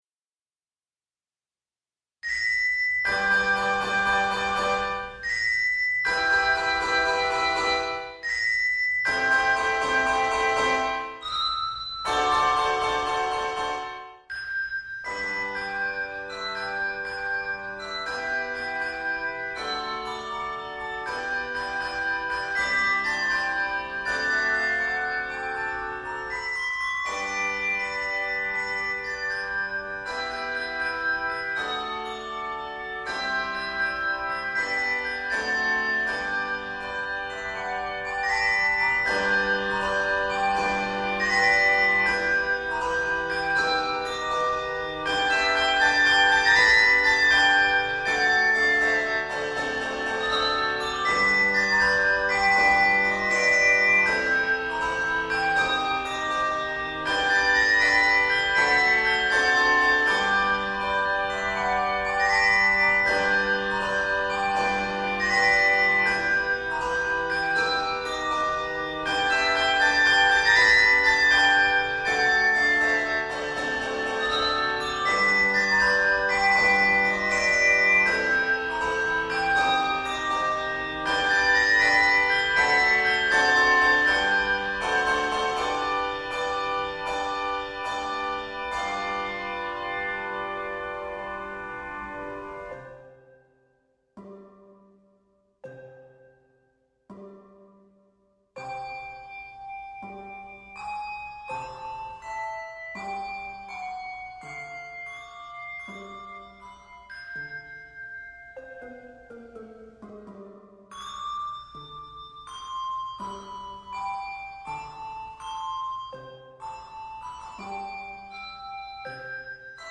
is a fun, swingin' medley of three spirituals
it is scored in G Major and C Major.